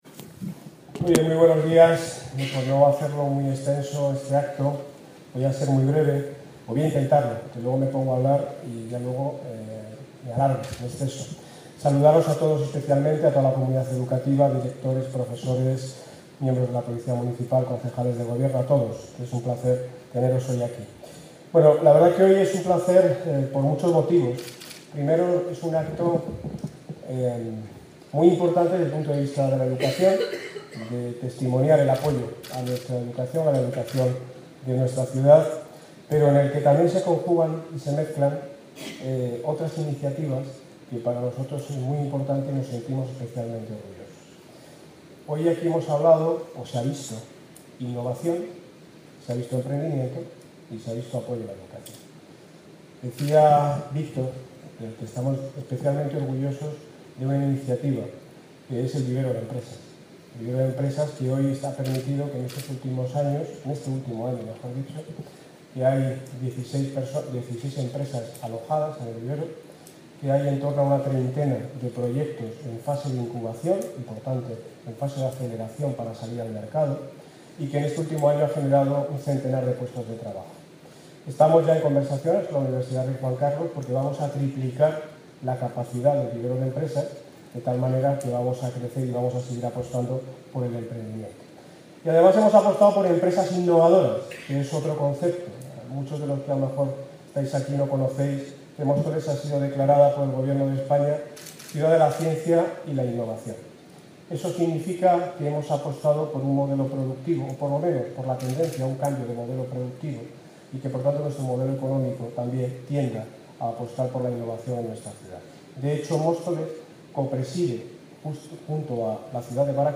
Audio - Daniel Ortiz (Alcalde de Móstoles) Sobre Absentismo Escolar